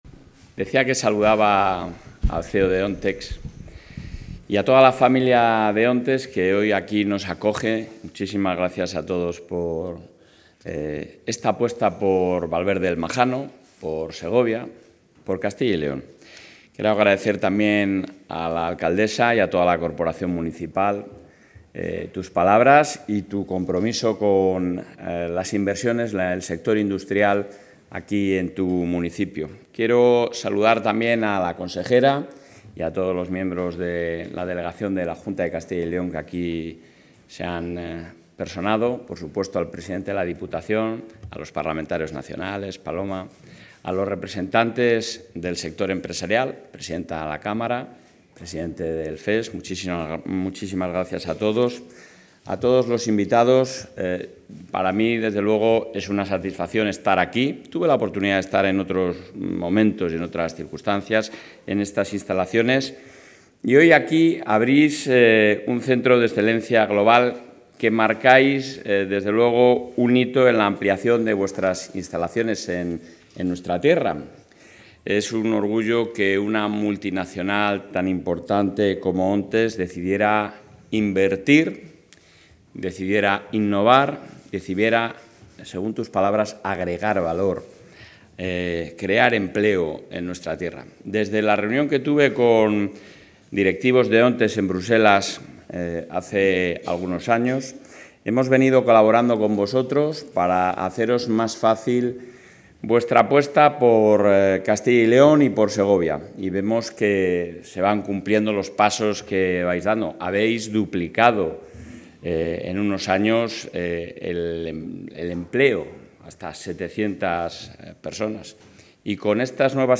Intervención del presidente.
El presidente del Gobierno autonómico, Alfonso Fernández Mañueco, ha participado hoy en la inauguración del Centro de Excelencia Global de la compañía ONTEX en el municipio segoviano de Valverde del Majano. Durante su intervención, ha destacado este proyecto como un ejemplo del atractivo de Castilla y León para atraer proyectos empresariales y crear empleo de calidad y especializado.